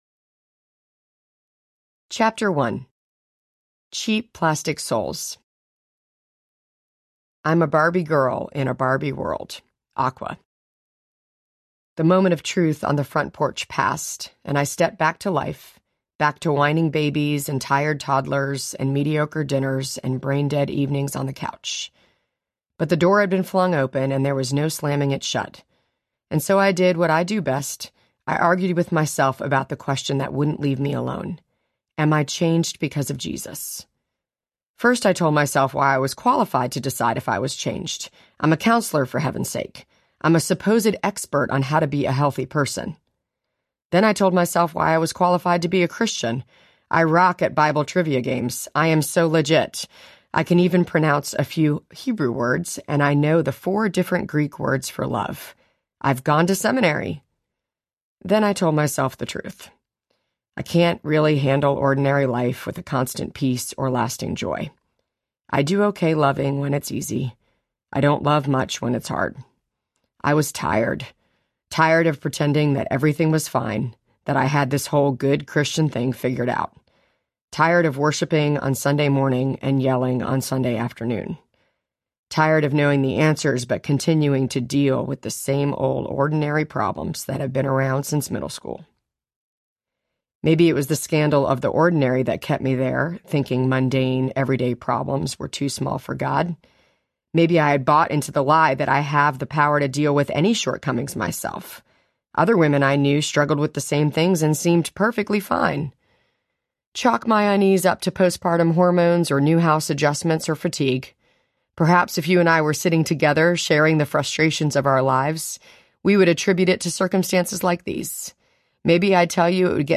She’s Got Issues Audiobook
7.8 Hrs. – Unabridged